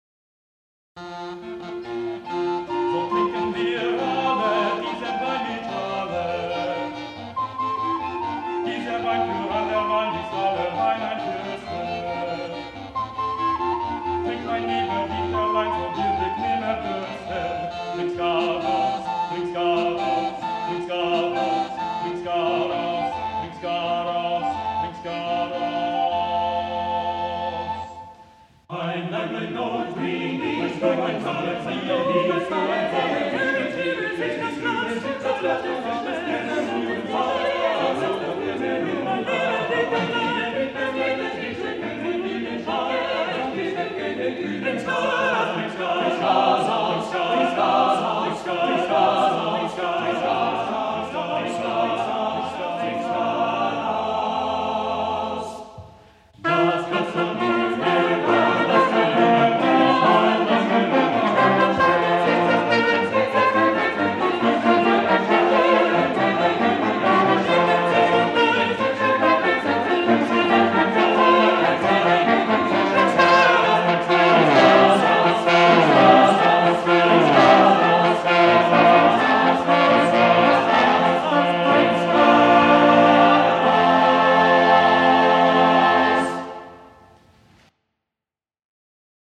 Printed in 1536 this lively drinking song may belong to the composer’s student days, or Archduke Ferdinand wanted something to accompany an evening of carousing.  A Tenorlied a5 with lots of clever contrapuntal writing, it’s a step up from a lot of the drinking songs published in Germany around this time .
baritone